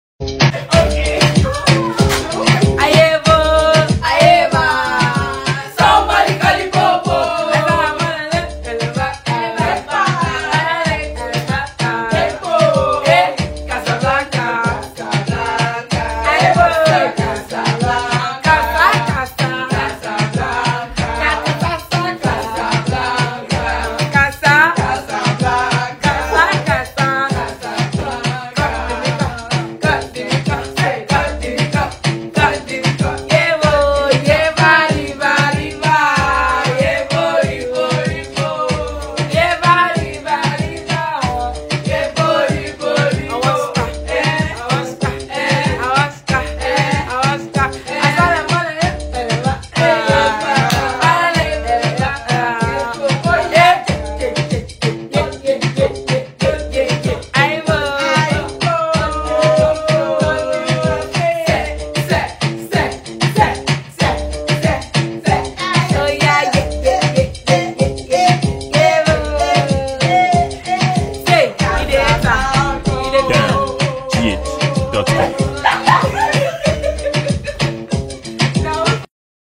Nigerian decorated vacalist
freestyle